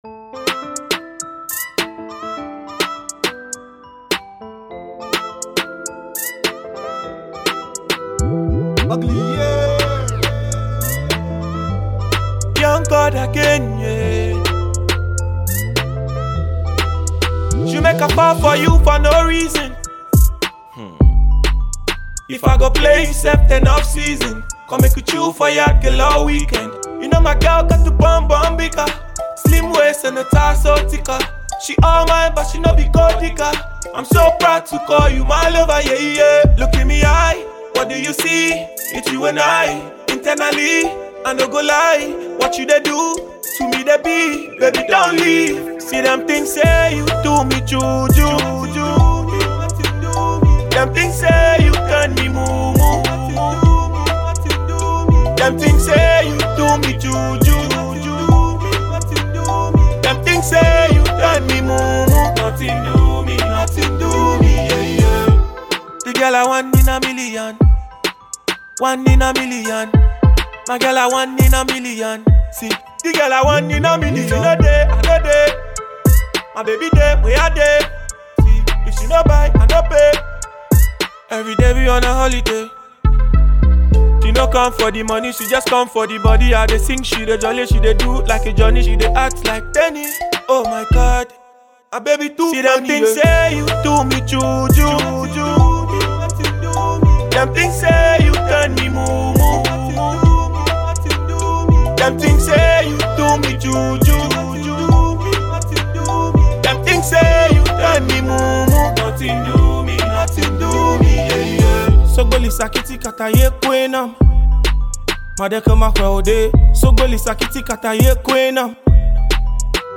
Ghanaian promising rapper